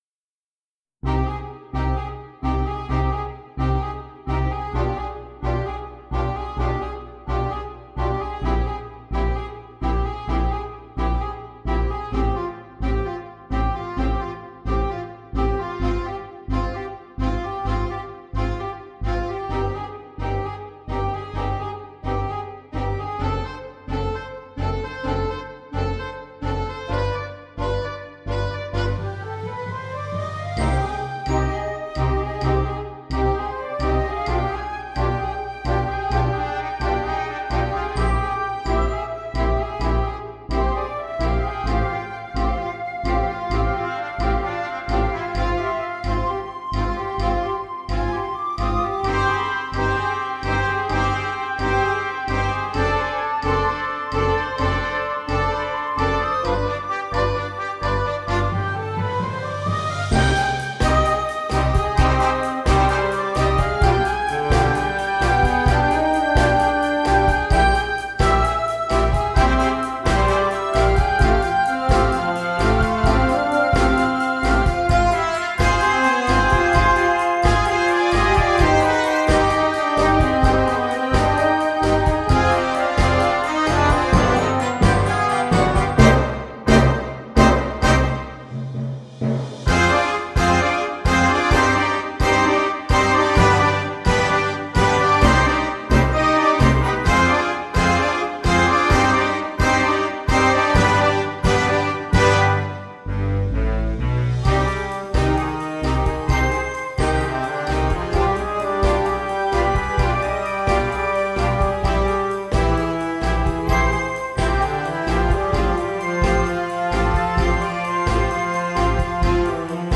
Accordeon and Choir ad libitum.
Noten für Blasorchester.